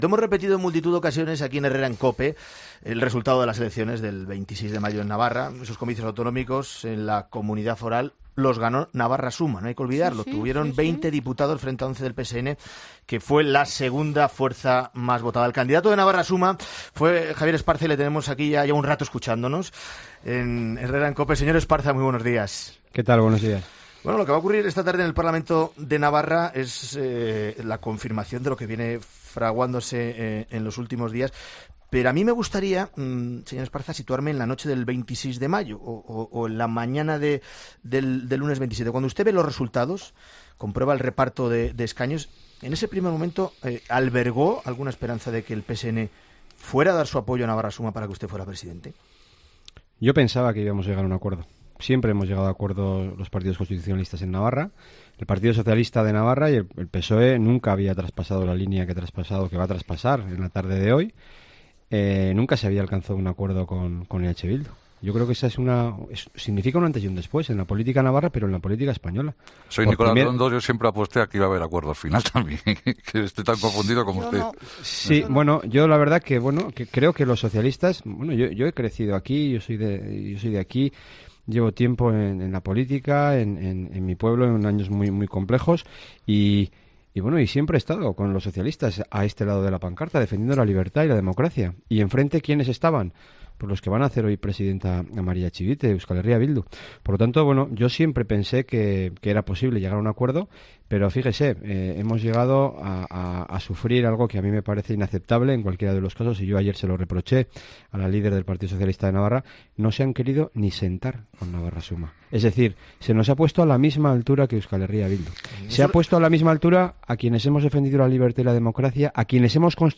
En una entrevista este viernes en 'Herrera en COPE', el líder de la coalición de centro-derecha ha reconocido que pensaba que iba a llegar a un acuerdo de gobierno con los socialistas y que nunca pensó que se fuera a traspasar la línea que se va a traspasar hoy.